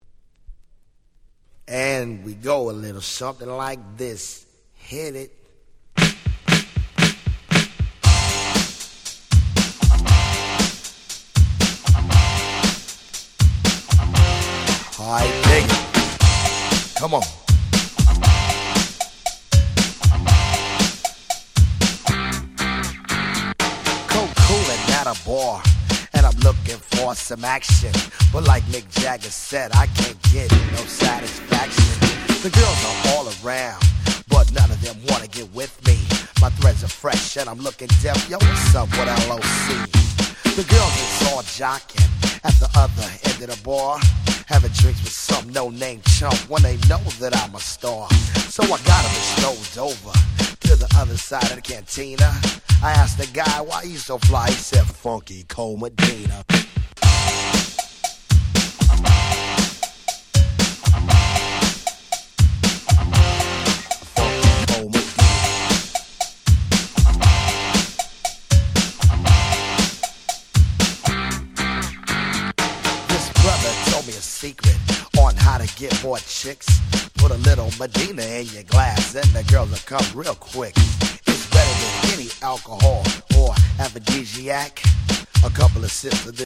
【Media】Vinyl 12'' Single
Hip Hopの歴史の1ページです。
オールドスクール